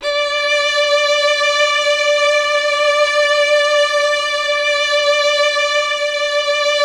MELLOTRON.11.wav